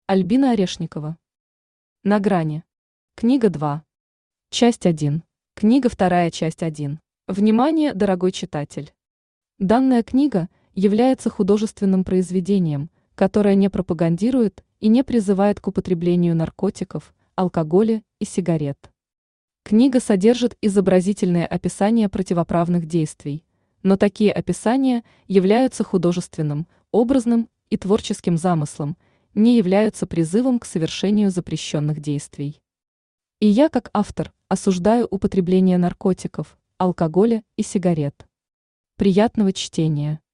Аудиокнига На грани. Книга 2. Часть 1 | Библиотека аудиокниг
Часть 1 Автор Альбина Анатольевна Орешникова Читает аудиокнигу Авточтец ЛитРес.